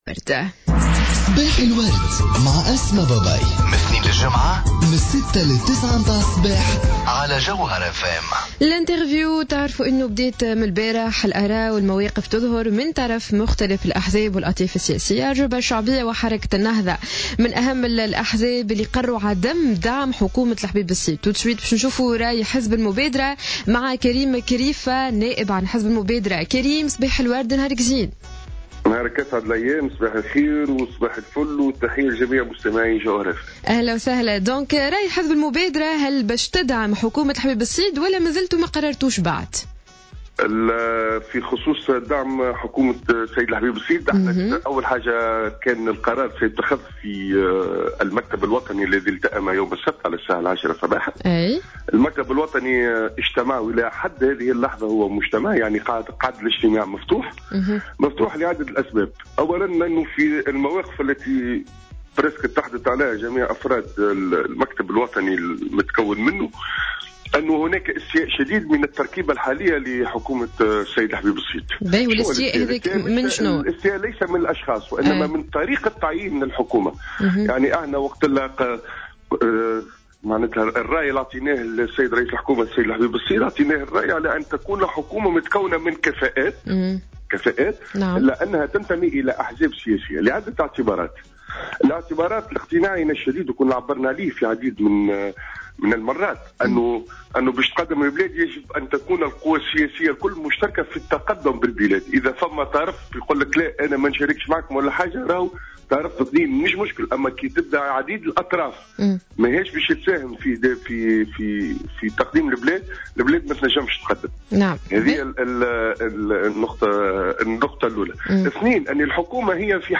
Le dirigeant du parti Al Moubadara (l'Initiative Destourienne), Karim Krifa a indiqué lundi au micro de Jawhara Fm que les membres du bureau politique poursuivent les discussions, lors d'une réunion ouverte depuis samedi, pour déterminer la position du parti par rapport au gouvernement Habib Essid.